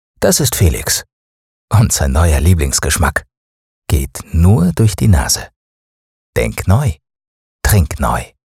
Werbung Hochdeutsch (CH)